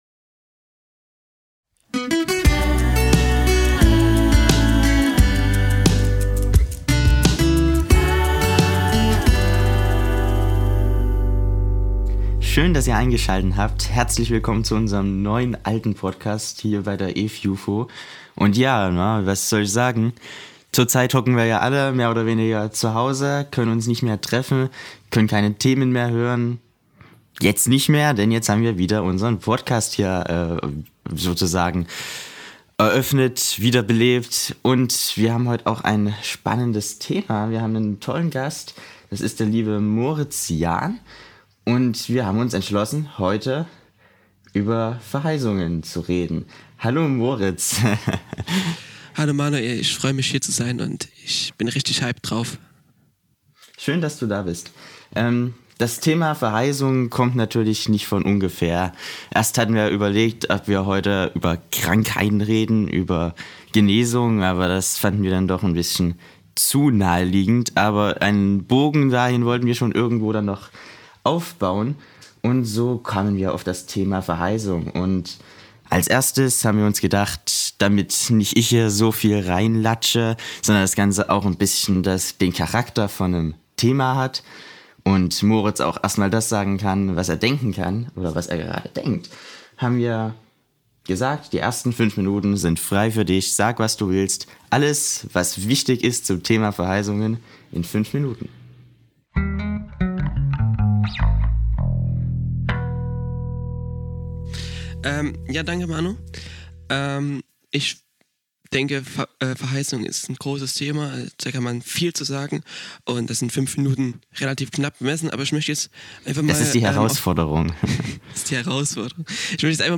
Mit einem festen Moderatorenteam gibt es wöchentlich einen kurzweiligen Podcast auf die Ohren. Dabei werden verschiedenste Themen erläutert, besprochen und diskutiert.